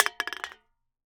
can_drop.wav